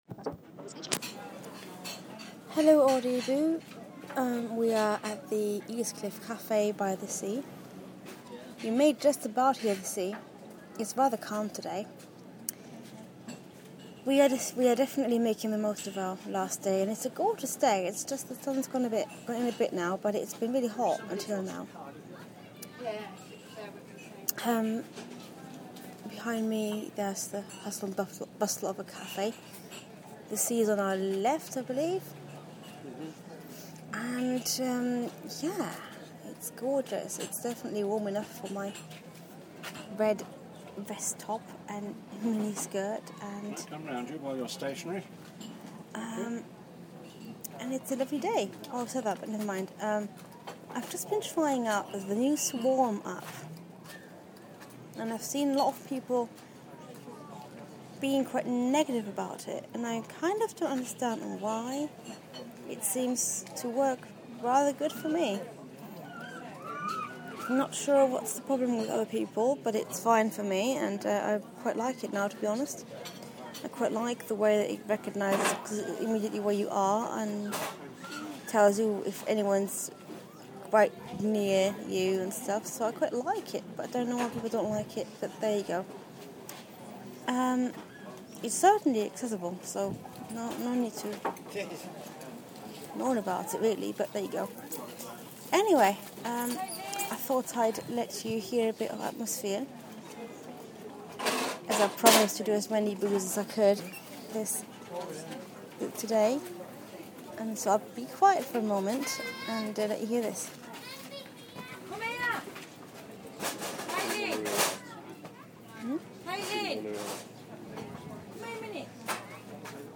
Atmosphere at Eastcliff Cafe